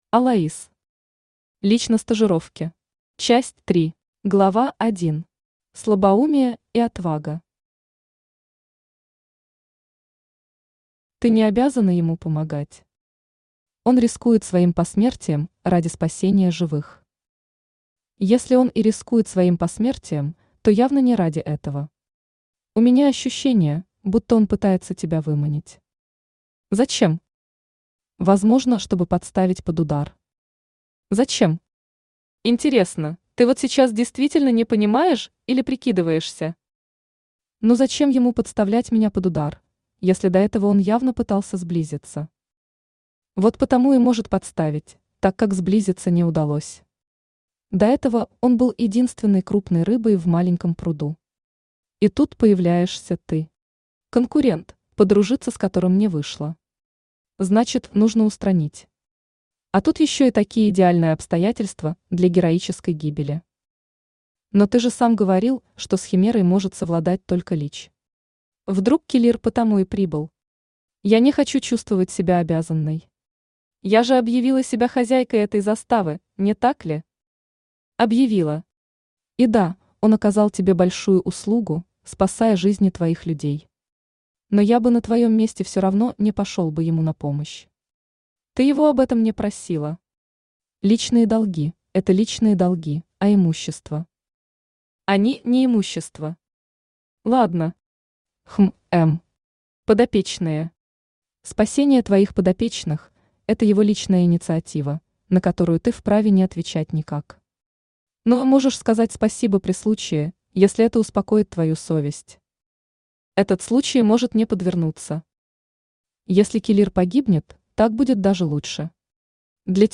Аудиокнига Лич на стажировке. Часть 3 | Библиотека аудиокниг
Aудиокнига Лич на стажировке. Часть 3 Автор Алаис Читает аудиокнигу Авточтец ЛитРес.